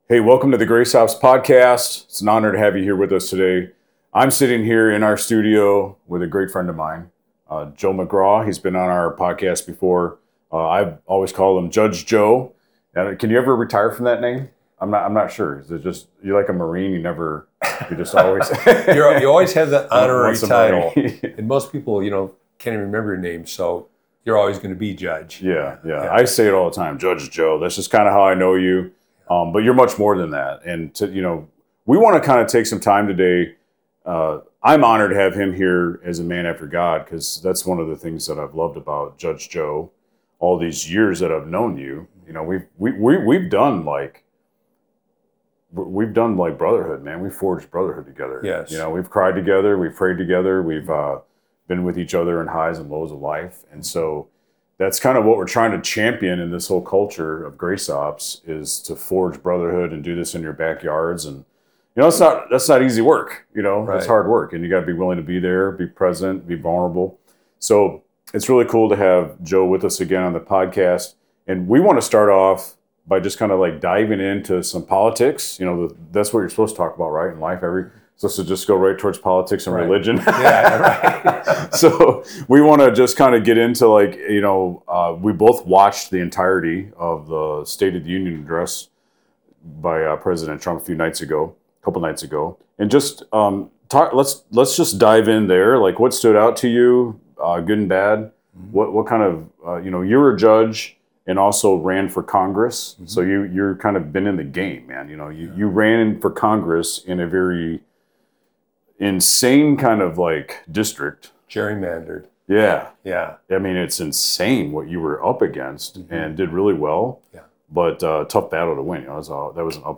Judge Joe McGraw joins us to break down the State of the Union and the State of the Church. We discuss where America is headed, the challenges facing the Church, and what it will take to stand firm in truth and justice. Don't miss this powerful conversation on faith, freedom, and the future of our nation.